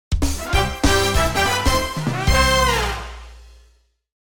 多分スター的な何かをゲットしたときに流れるであろう明るめのジングル。